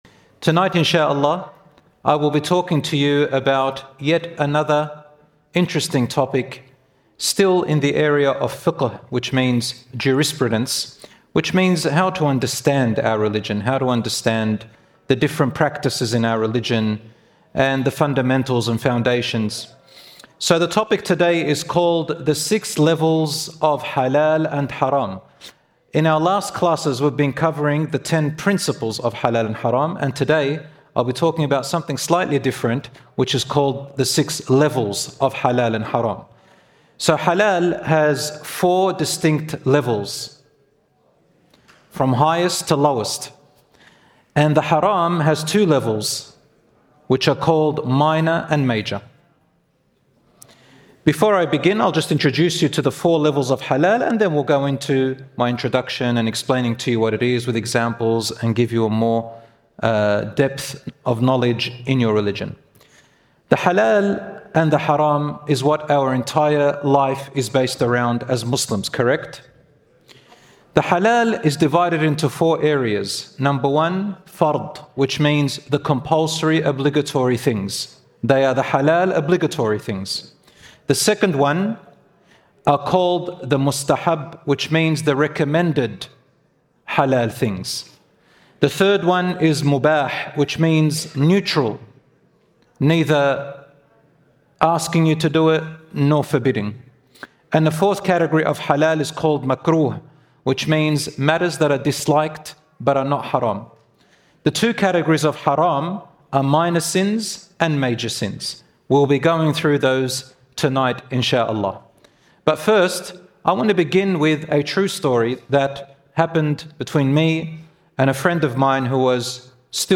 In the second leg of my tour in Birmingham (UK), we look at taking practical steps to create a healthy and harmonious family dynamic.